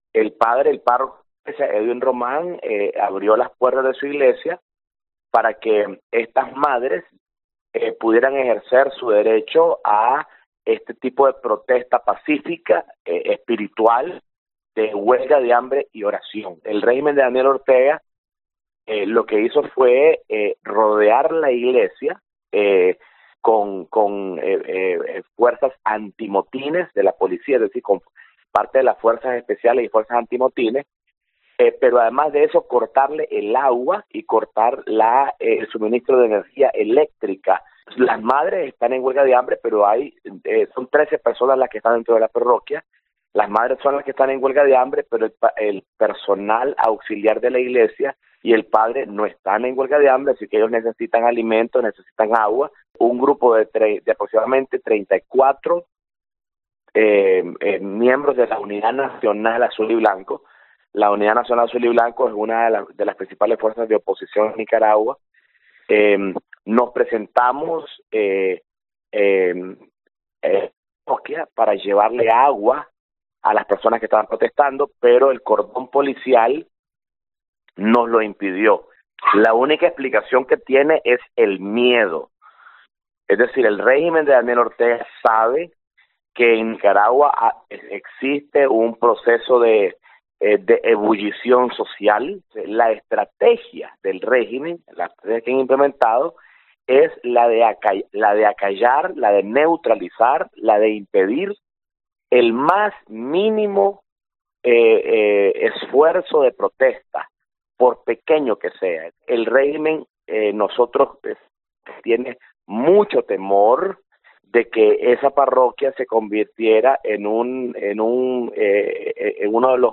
Fuerzas antimotines han cortado agua y electricidad en la parroquia de San Miguel Arcángel, asegura a COPE el opositor Félix Maradiaga